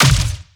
LASRGun_Plasma Rifle Fire_03.wav